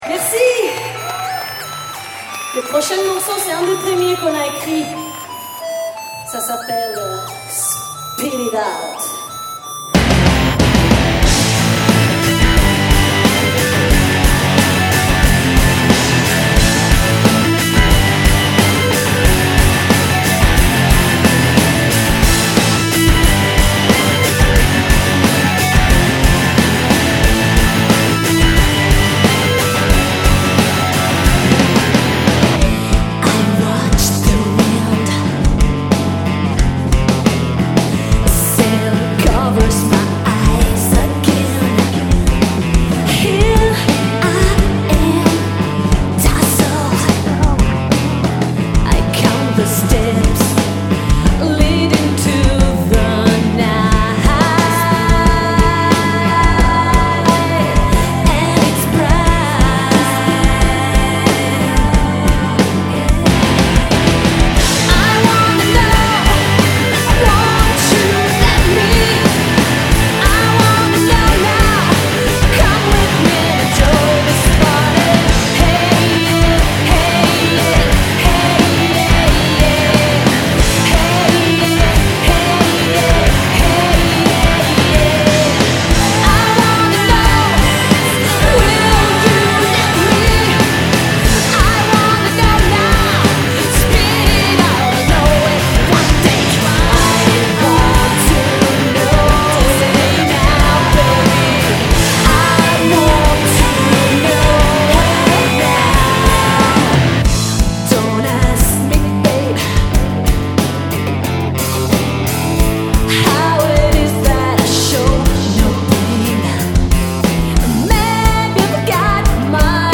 Vocals
Lead Guitars
Drums
Rythm Guitars
bass and samples
Recorded Live